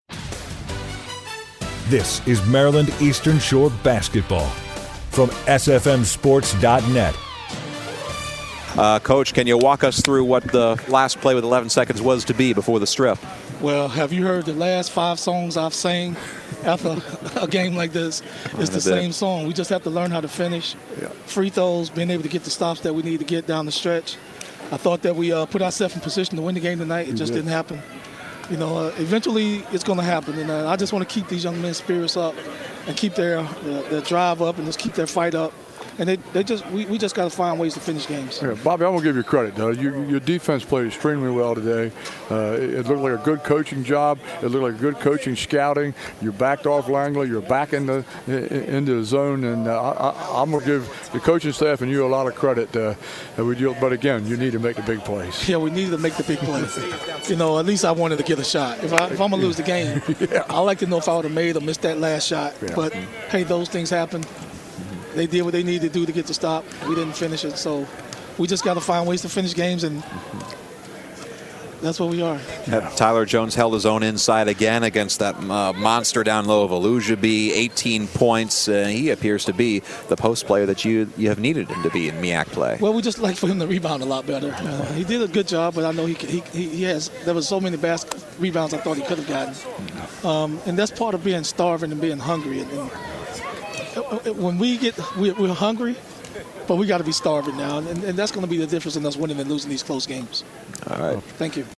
Post Game Show